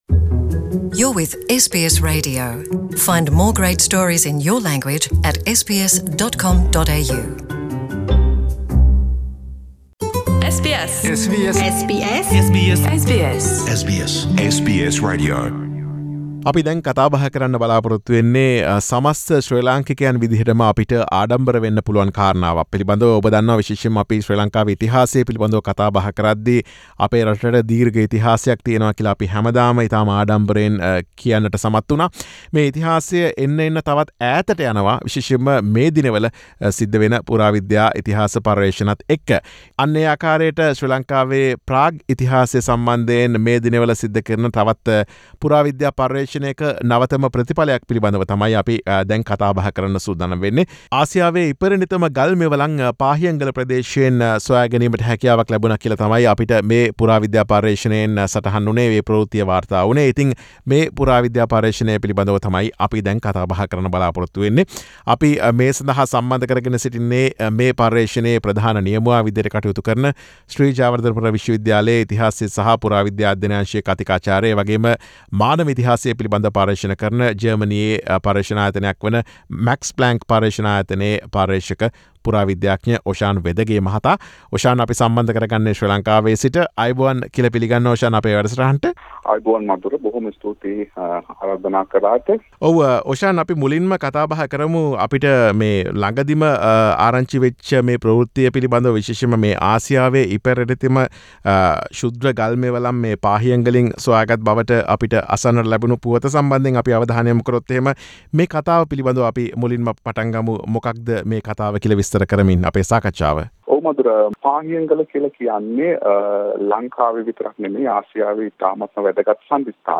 මෙම පර්යේෂණය පිළිබඳ SBS සිංහල වැඩසටහන සමග සිදුකළ සාකච්ඡාව.